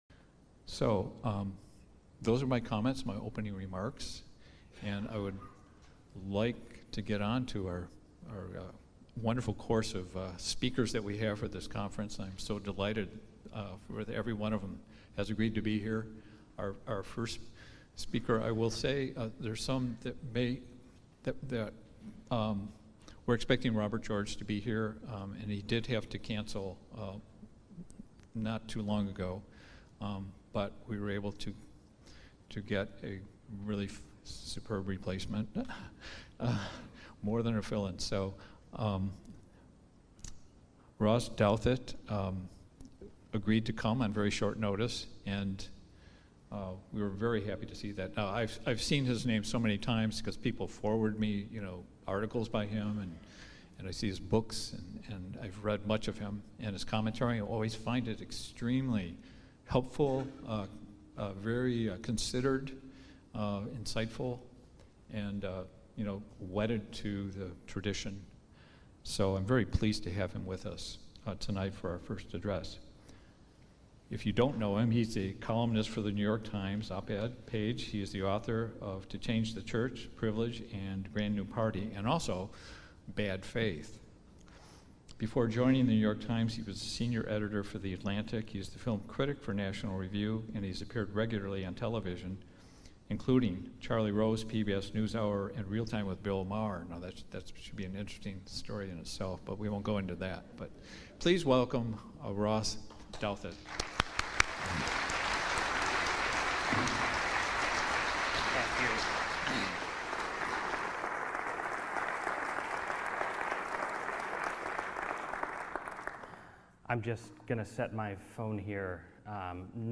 Duration: 49:51 — Talk delivered on Thursday, October 10, 2019